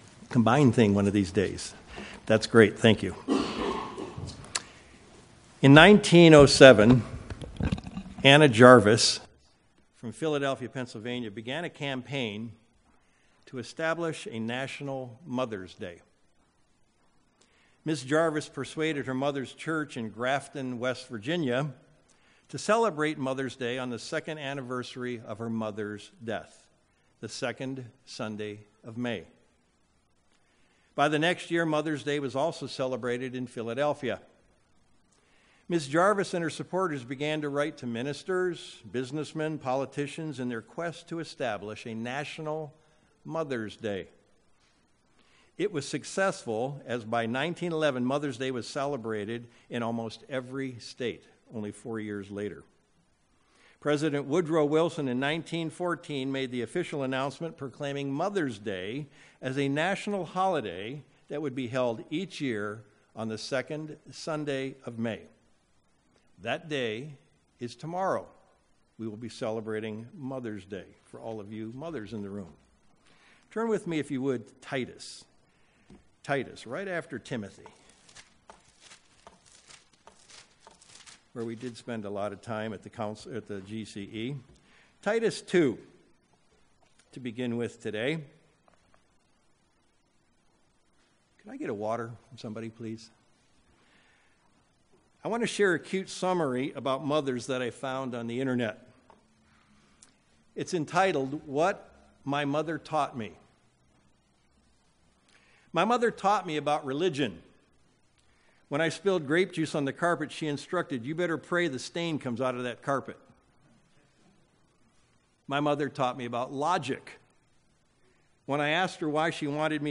View on YouTube UCG Sermon Studying the bible?